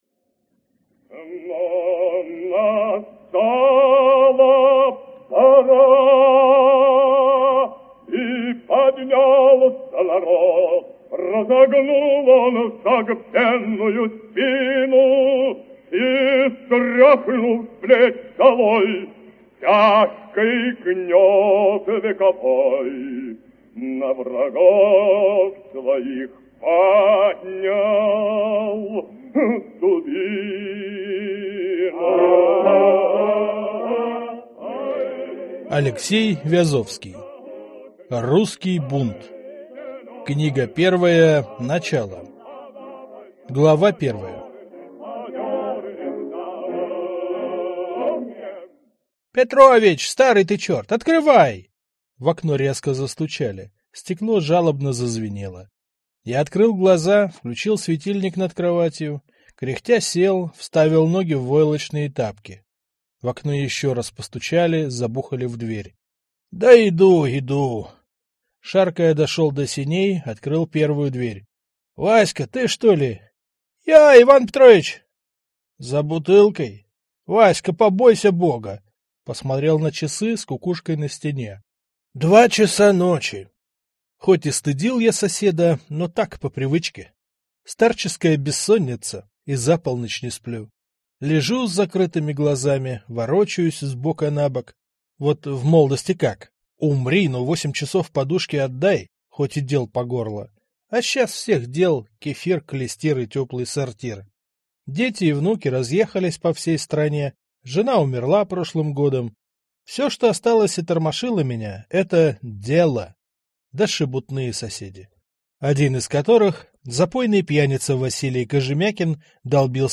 Аудиокнига Русский бунт. Начало | Библиотека аудиокниг
Прослушать и бесплатно скачать фрагмент аудиокниги